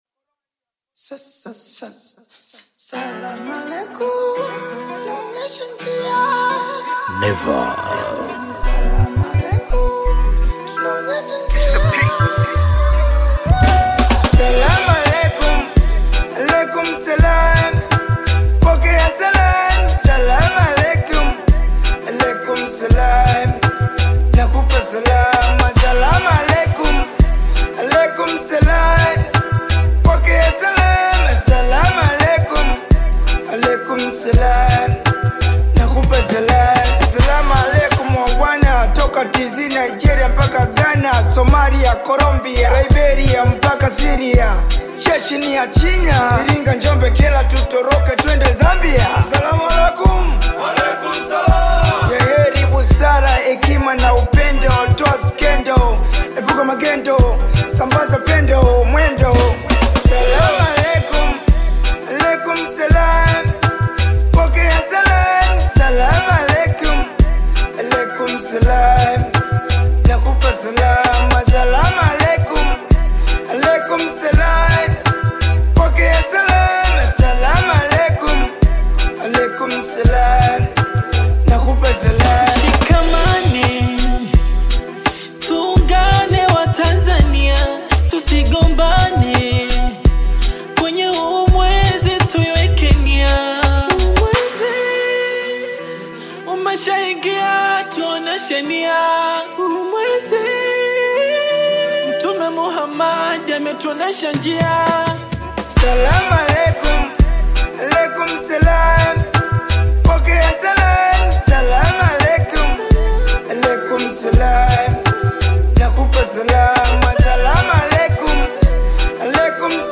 Veteran bongo flava artist